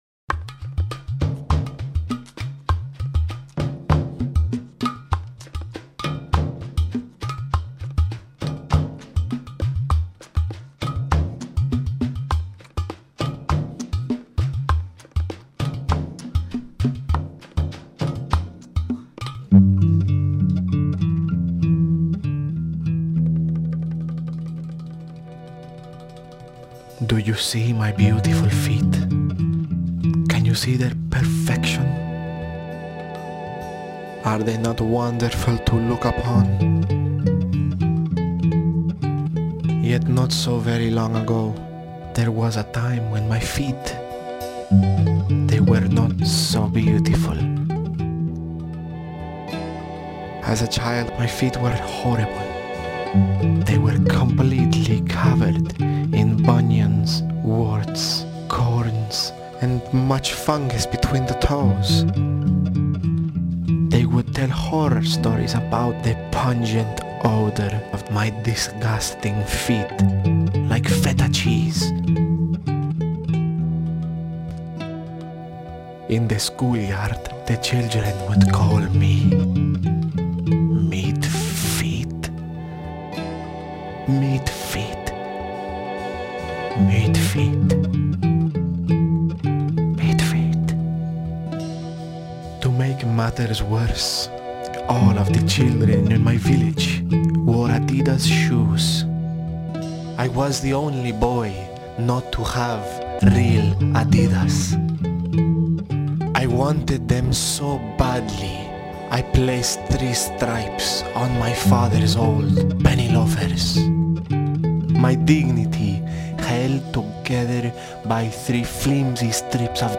original soundtrack
Voiceover